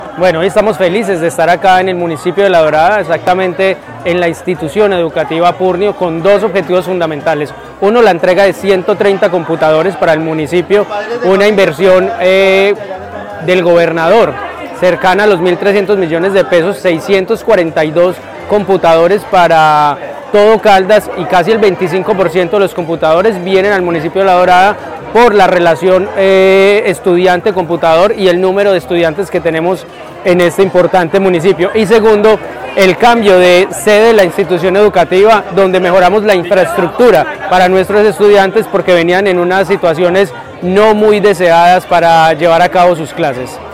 Ronald Bonilla, gobernador (e) de Caldas